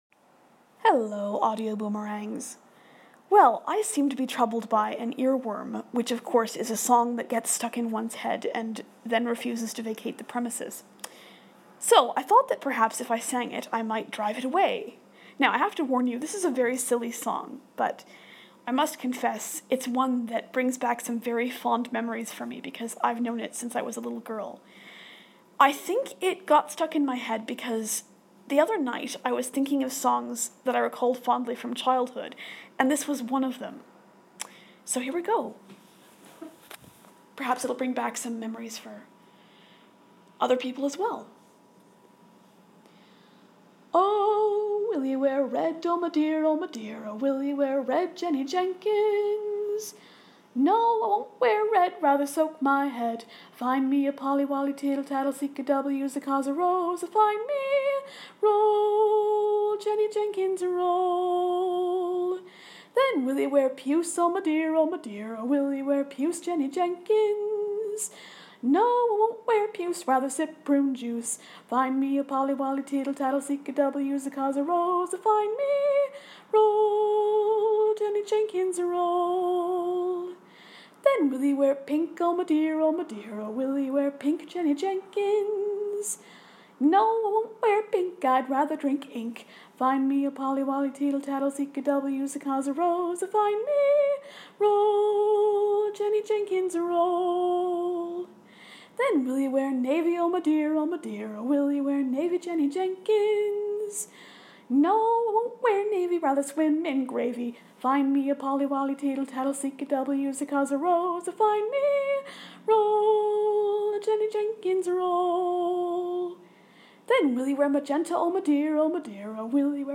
singing a silly song that's stuck in my head